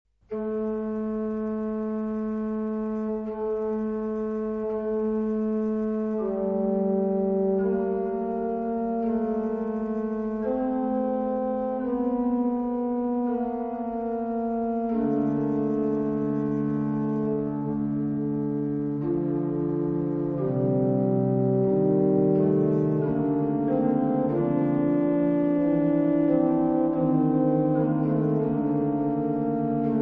organo